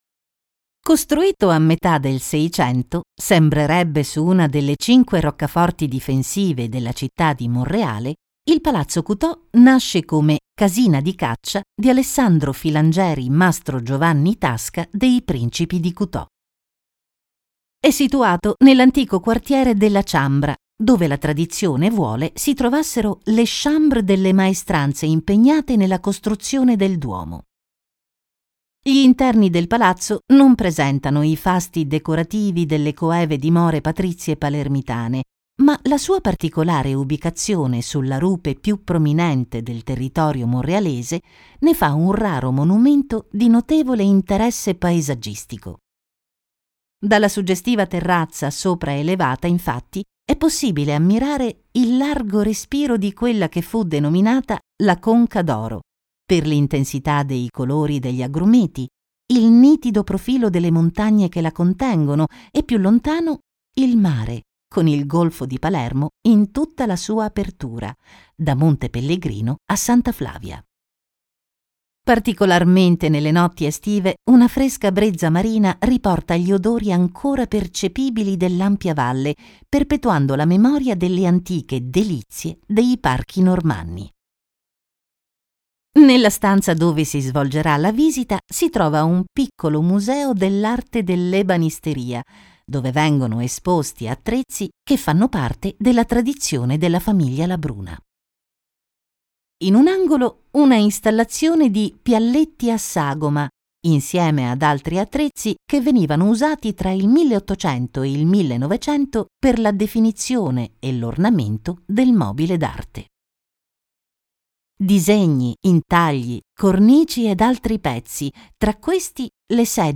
Naturelle, Polyvalente, Fiable, Mature, Douce
Guide audio